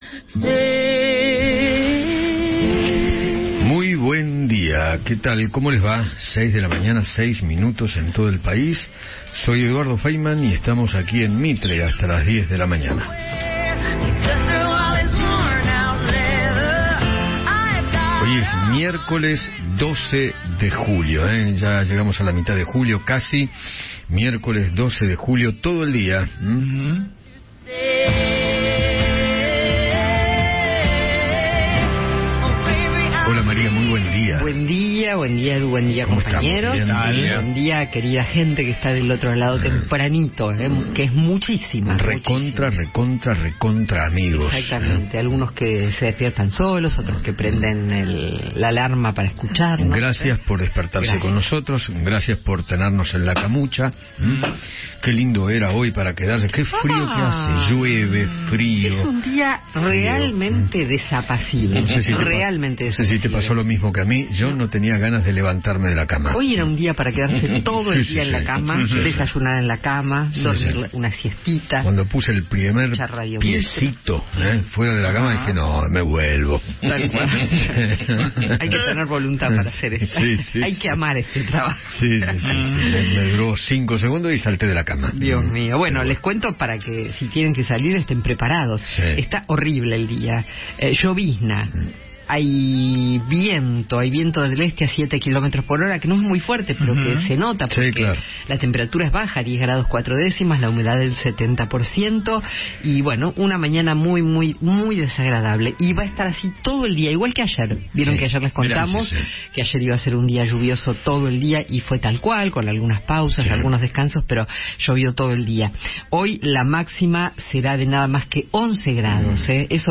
El conductor de Alguien Tiene que Decirlo criticó a los militantes de La Cámpora por su carácter revolucionario y recordó el pasado montonero del suegro de Sergio Massa, Fernando “Pato” Galmarini.